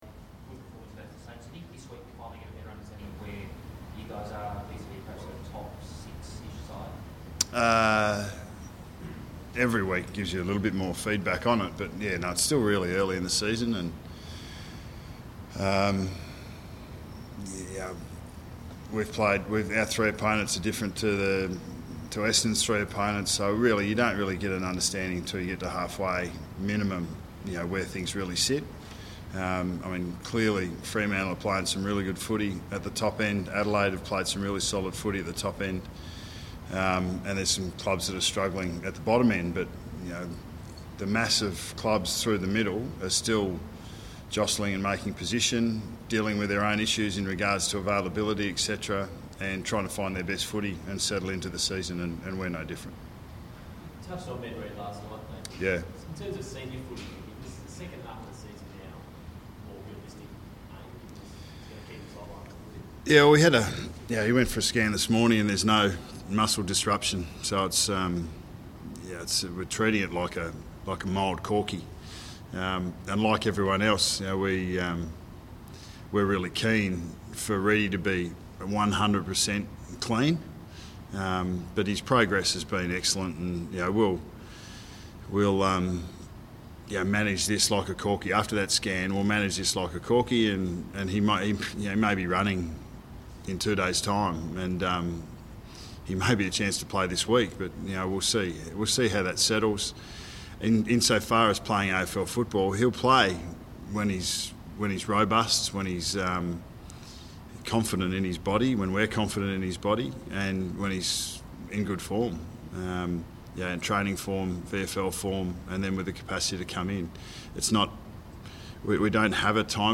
Press Conference: Nathan Buckley
Hear Nathan Buckley speak to the media ahead of Collingwood's clash with Essendon on ANZAC Day.